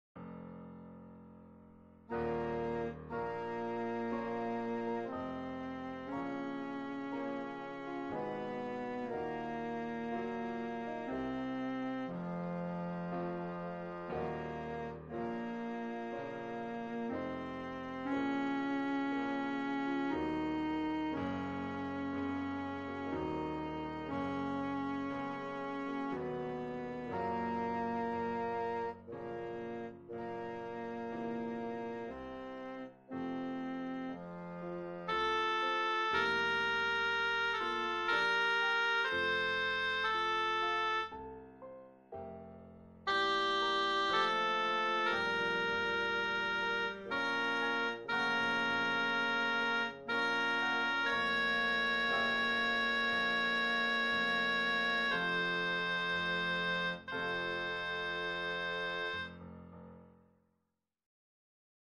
avec chaque voix accentuées
et parfois l'orchestre en fond